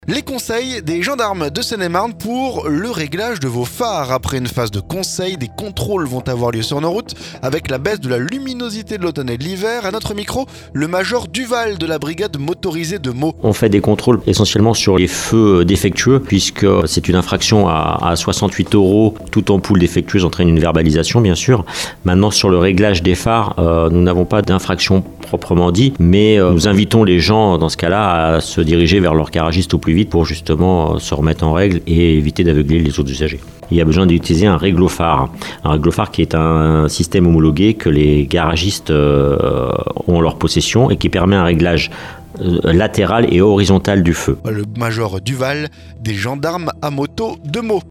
SECURITE ROUTIERE - Les conseils d'un gendarme pour le réglage de vos phares